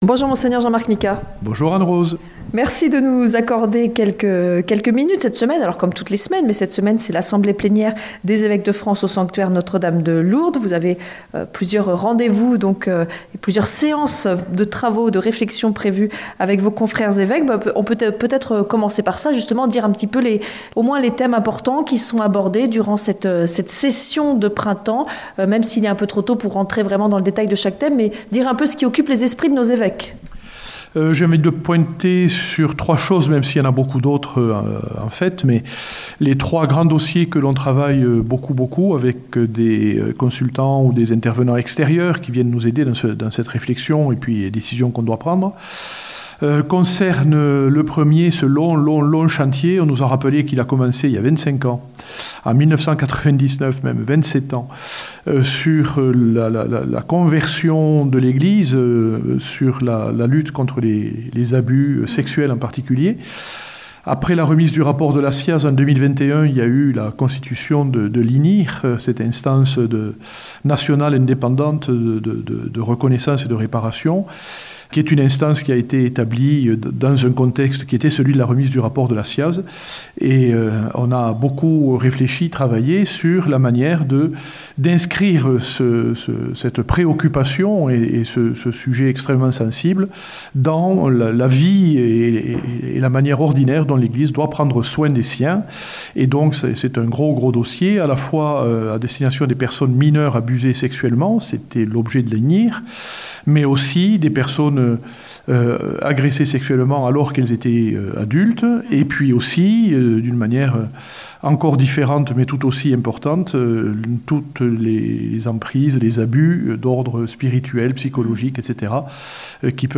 Entretien avec Mgr Micas - Évêque de Tarbes Lourdes